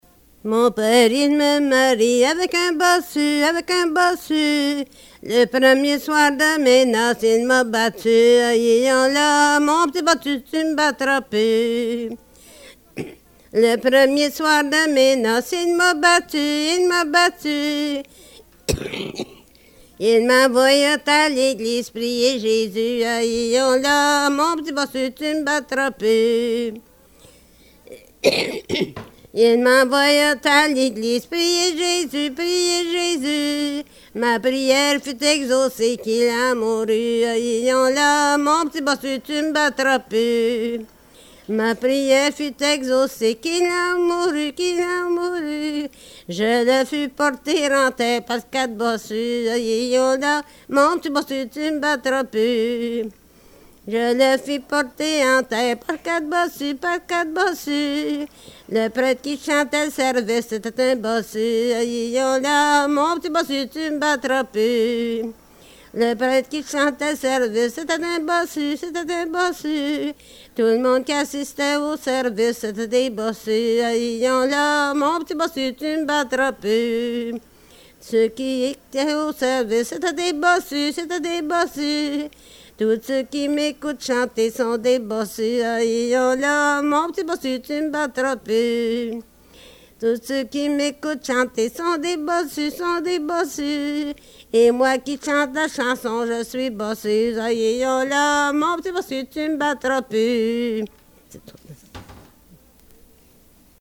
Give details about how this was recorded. Excerpt from interview